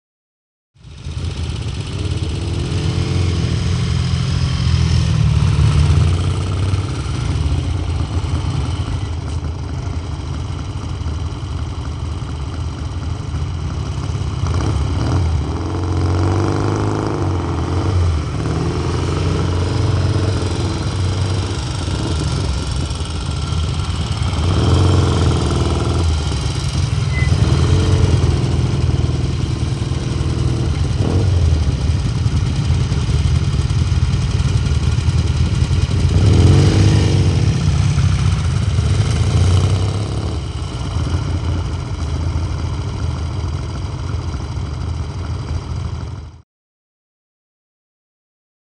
Motorcycles; By; Eight Motorbikes Revving And Coming Past Mic. Slow. All Circling Mic. Fruity Sounding Machines.